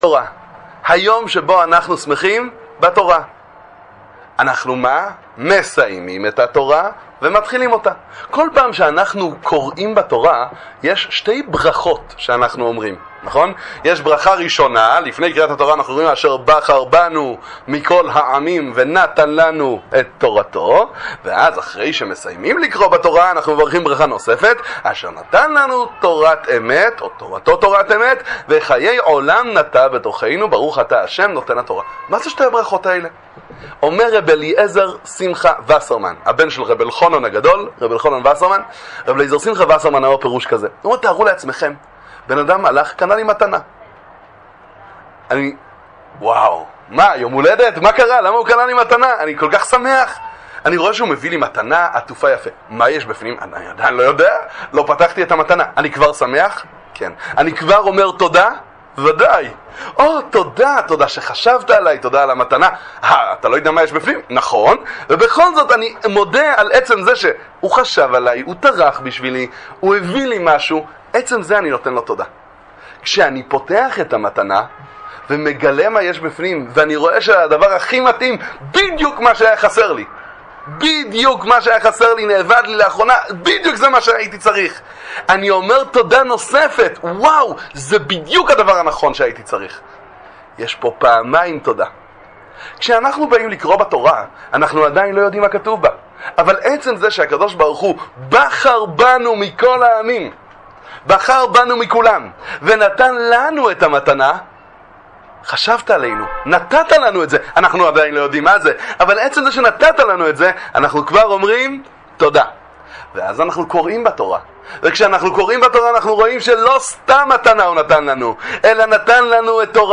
דבר תורה קצר לחג שמחת תורה!